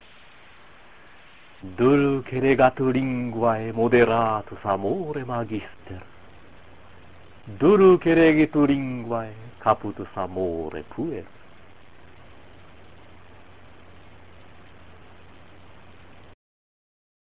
朗読７-８行